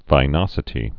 (vī-nŏsĭ-tē)